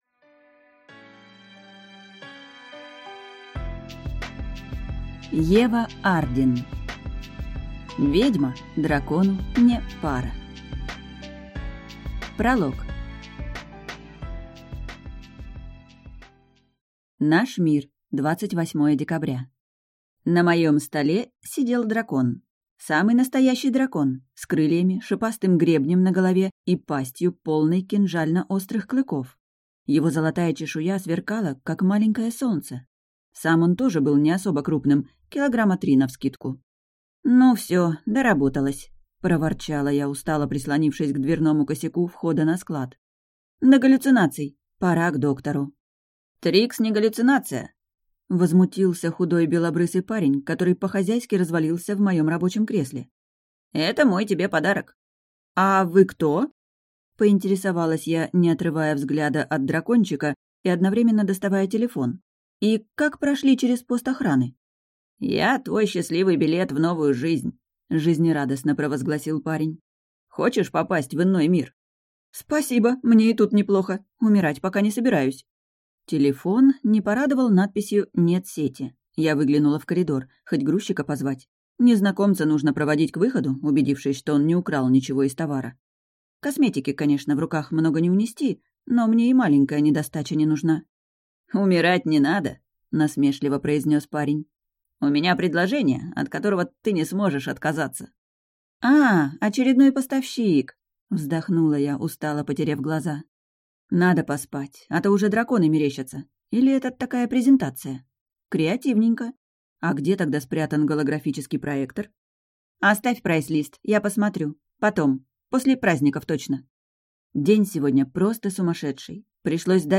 Аудиокнига «Ведьма дракону (не) пара!».